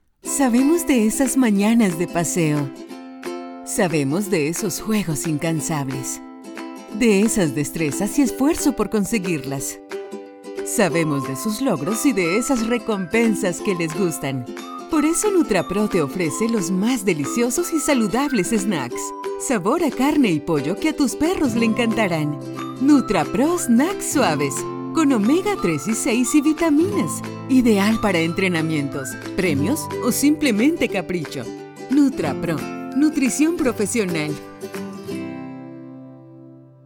VO IN SPANISH
Home recording studio. Professional Rhode microphones for excellent quality.